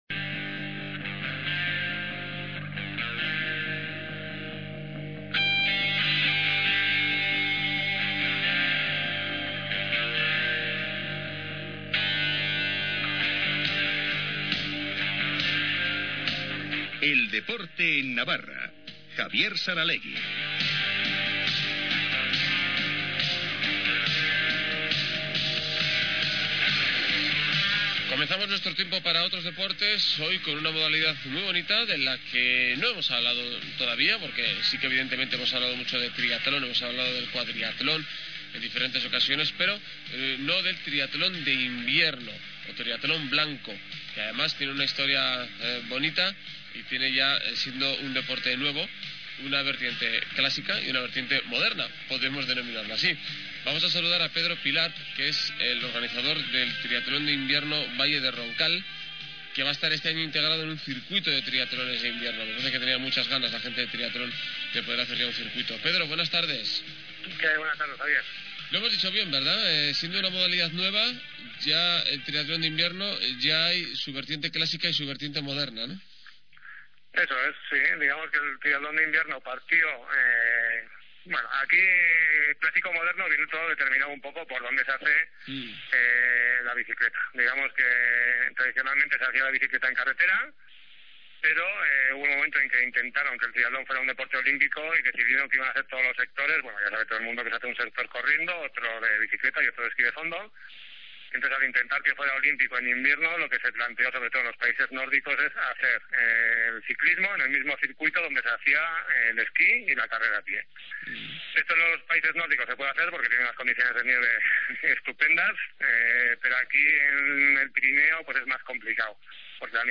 Entrevista en Ondacero Navarra - Triatlón invierno